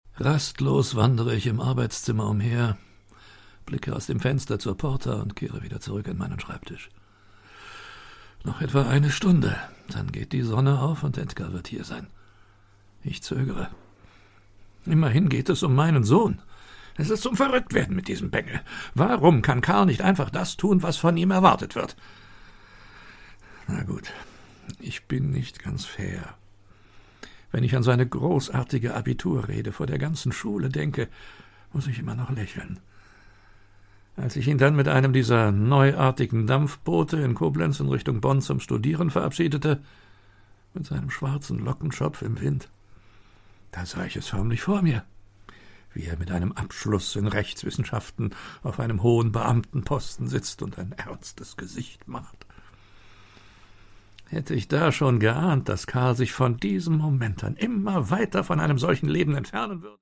Produkttyp: Hörbuch-Download
Wer war also dieser bürgerliche Gelehrte und welche Ideen entwickelte er? Friedrich Engels, seine Frau Jenny und andere Weggefährten erzählen anschaulich aus ihrem Leben mit Marx.